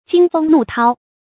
驚風怒濤 注音： ㄐㄧㄥ ㄈㄥ ㄋㄨˋ ㄊㄠ 讀音讀法： 意思解釋： 喻生活中的艱辛險惡。